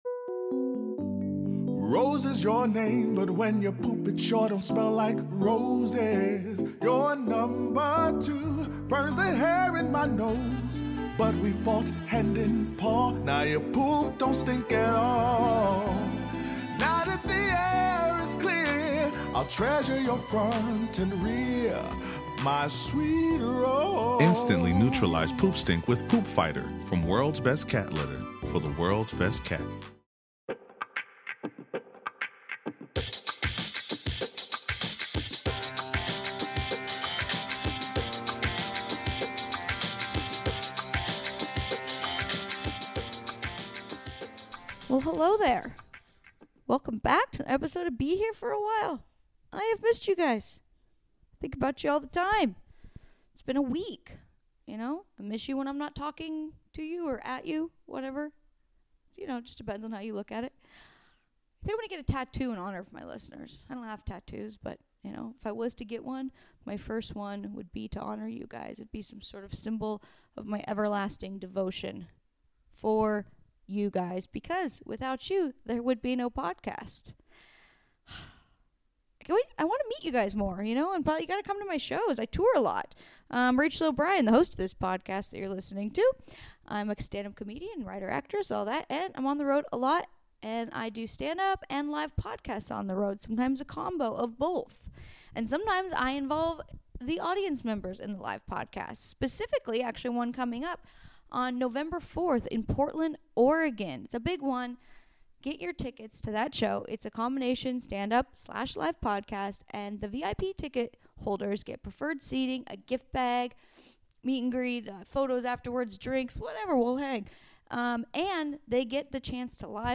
a fun conversation about dating, prison letters, plastic surgery and more